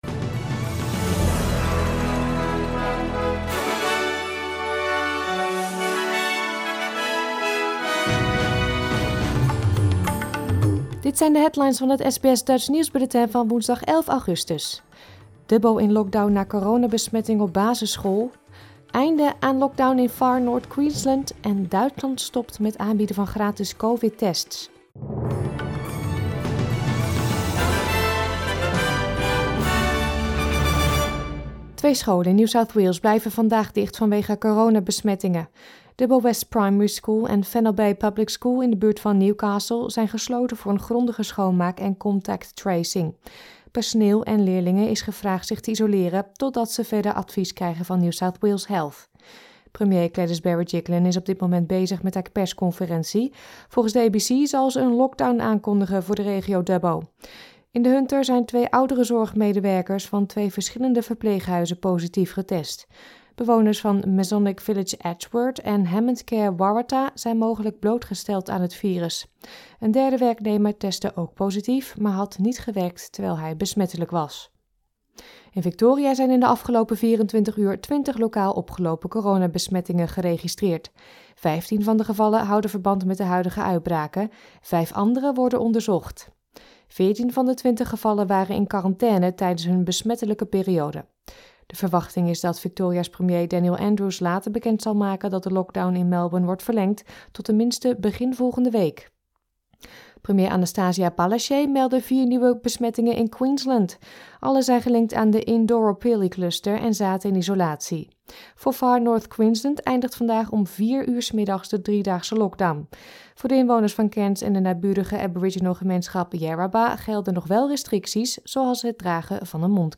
Nederlands/Australisch SBS Dutch nieuwsbulletin van woensdag 11 augustus 2021